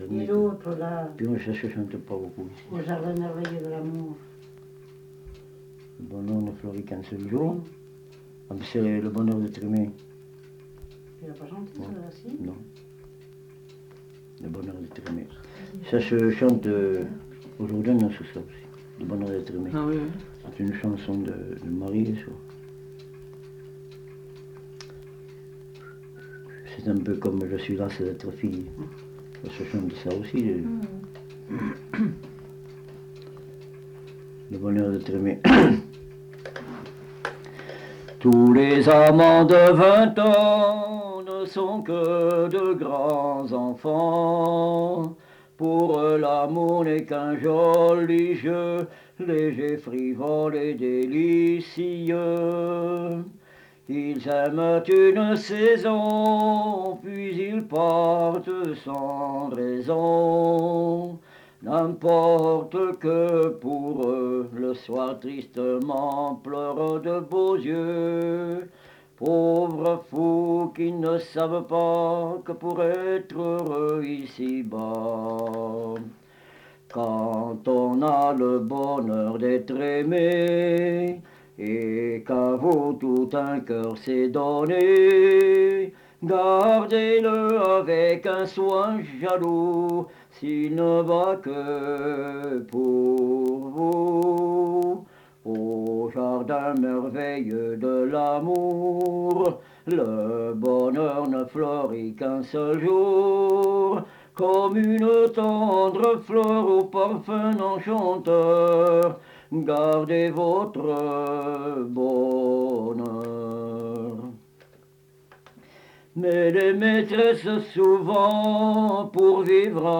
Lieu : Vernholles (lieu-dit)
Genre : chant
Effectif : 1
Type de voix : voix d'homme
Production du son : chanté
Description de l'item : version ; 3 c. ; refr.